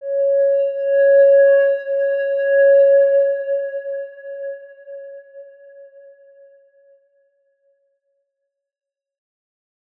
X_Windwistle-C#4-mf.wav